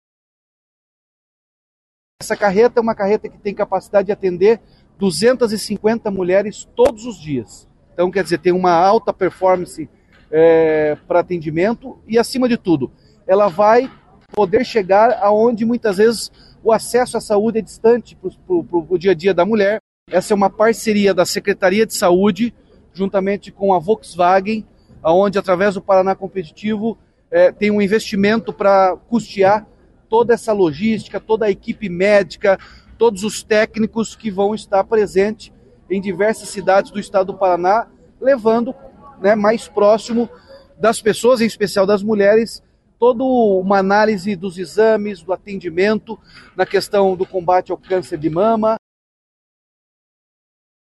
O governo do Estado apresentou há pouco em evento no Palácio Iguaçu uma novidade para os atendimentos de saúde públicos.